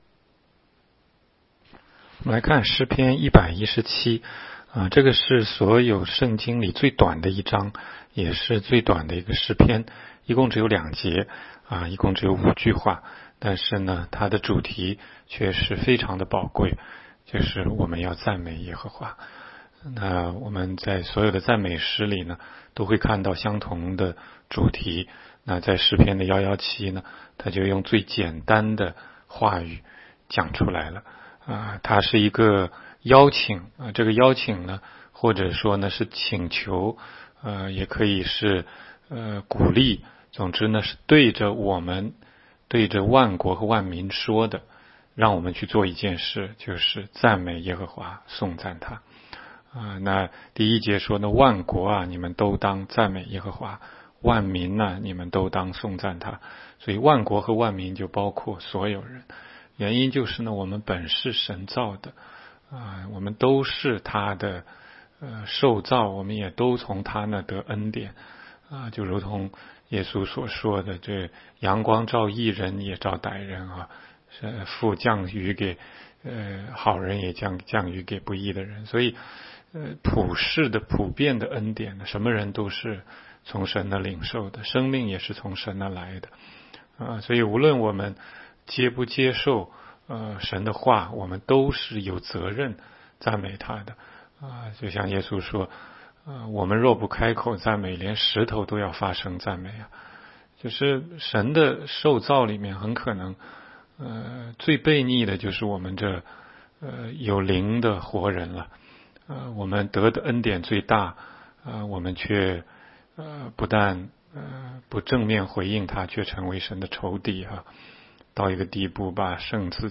16街讲道录音 - 每日读经 -《 诗篇》117章